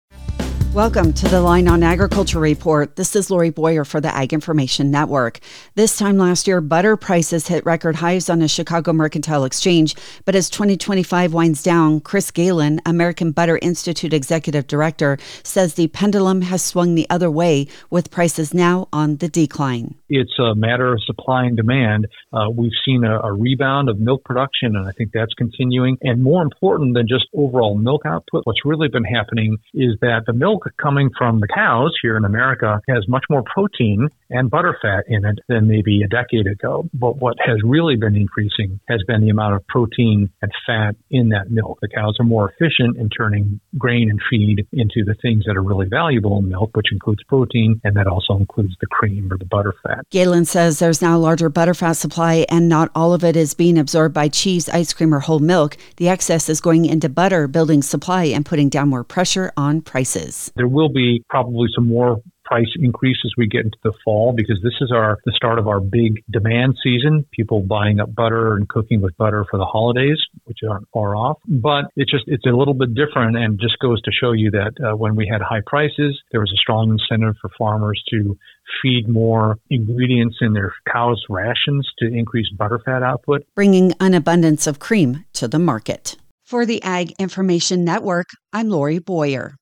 Reporter